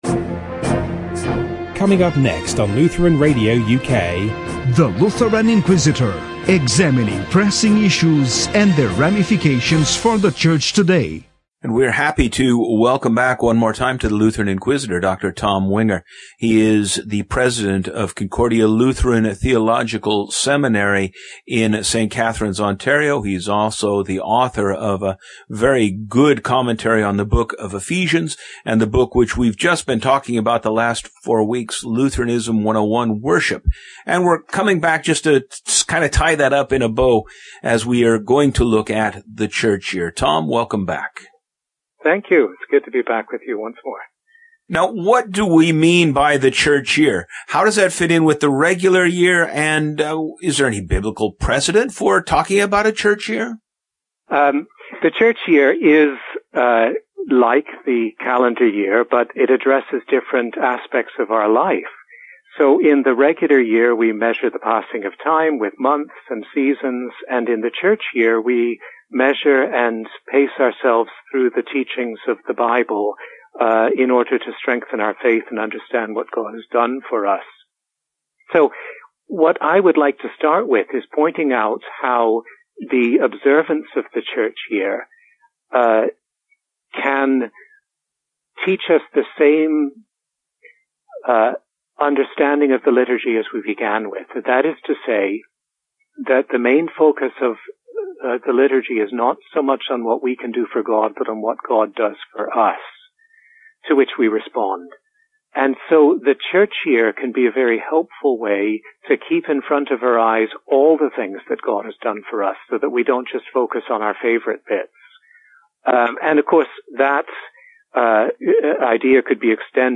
Lutheranism 101: Worship Interview, Part 5